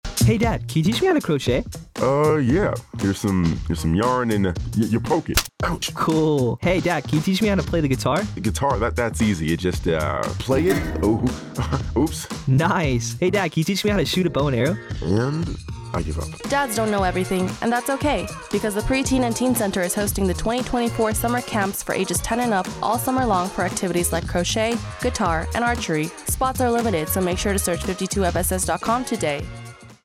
a 30 second spot on Summer Camp courses taking place at the pre-teen and teen center.